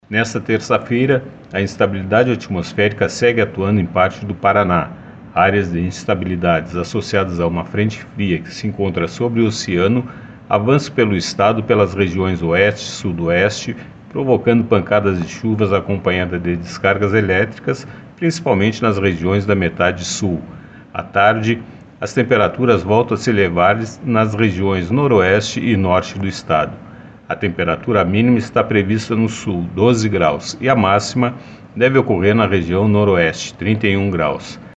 Ouça os detalhes com o meteorologista do Simepar